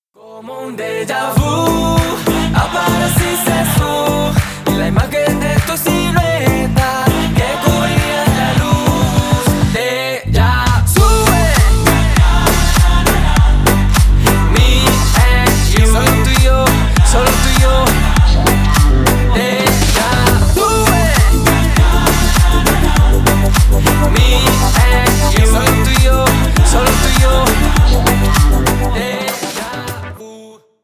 • Качество: 320 kbps, Stereo
Поп Музыка
латинские